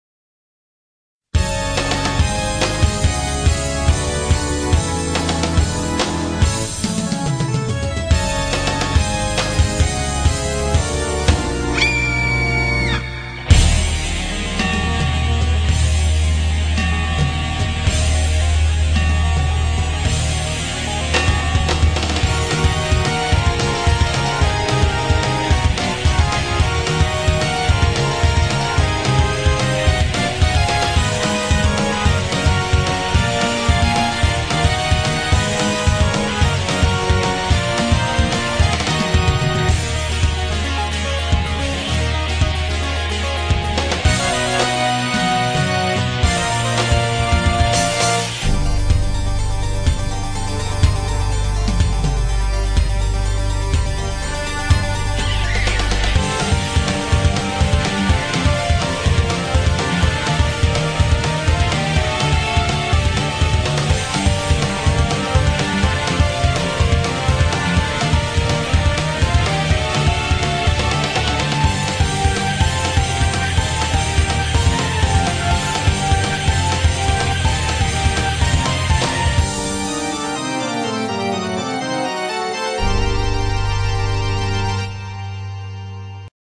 It at first sounds like a wedding song
gothic rock